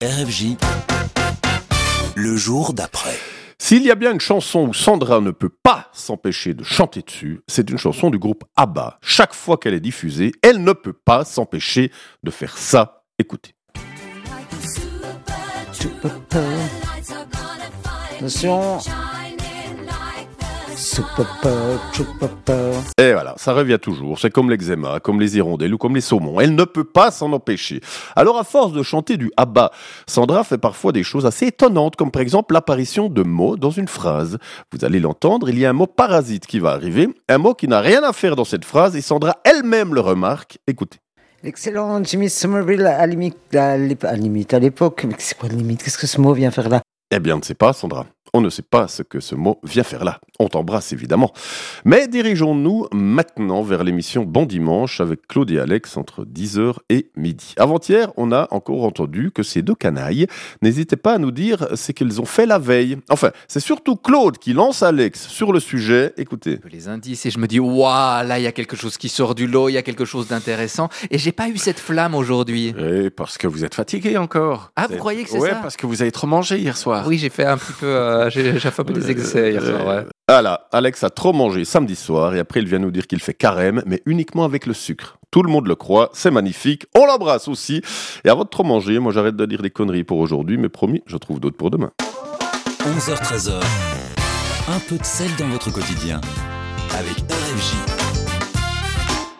L'actu de la veille, traitée de manière un peu décalée, sons à l'appui, c'est ça "Le jour d'après !"